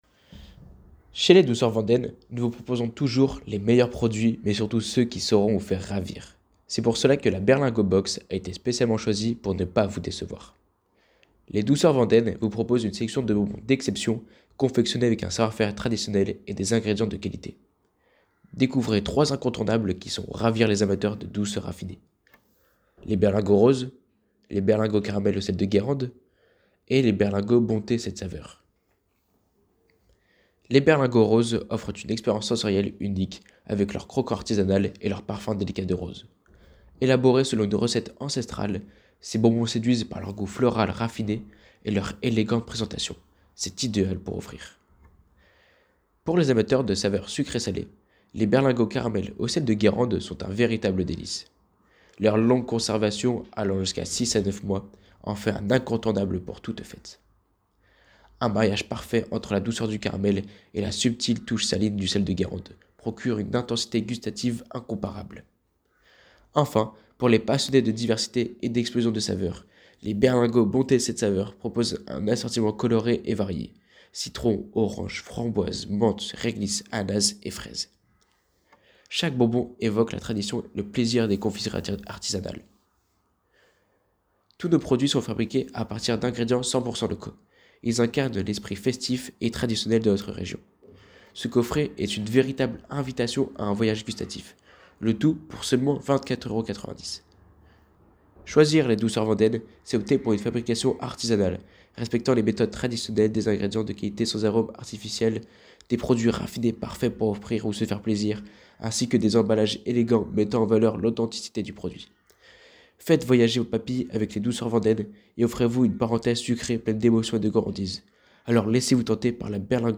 argumentaire-commerciale-berlingot-box.mp3